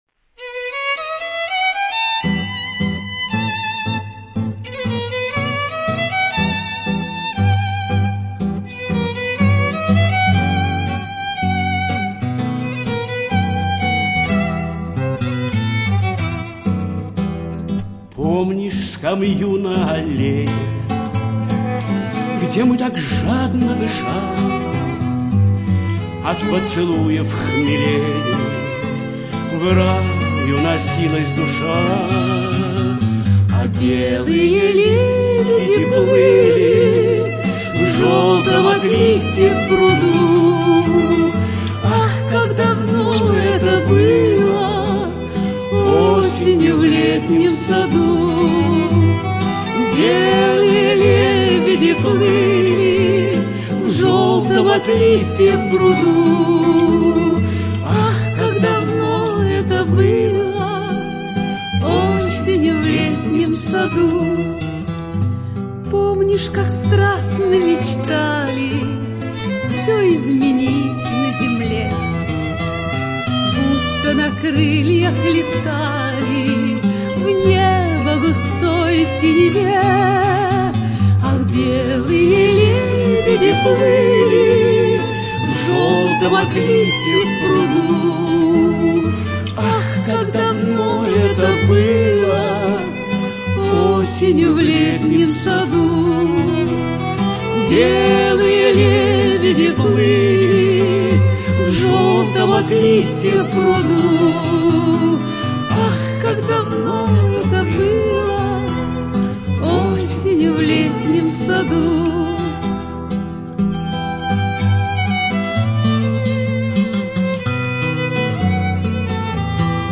в дуэте